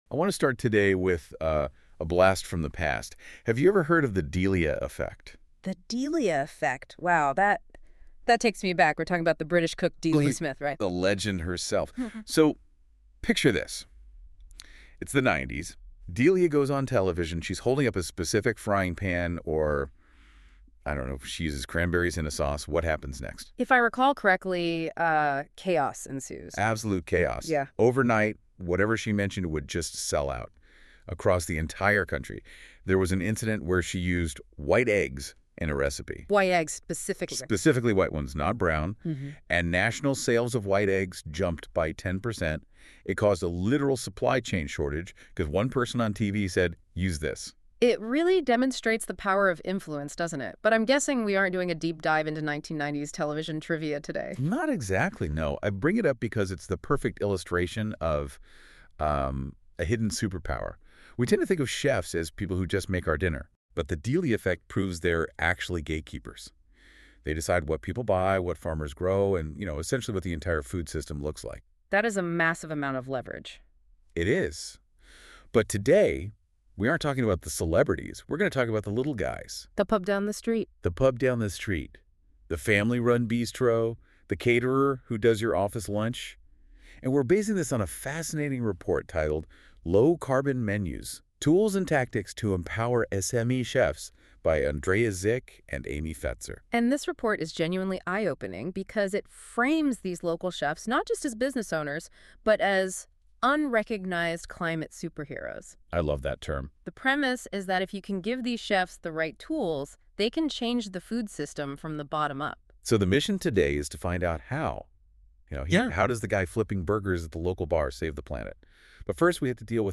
If you’re too busy to read a full report, maybe you can listen to it instead — with the help of AI.
Rather than wading through pages of data, this short episode translates the research into an engaging conversation — unpacking how chefs and food businesses can use menus as a practical climate solution.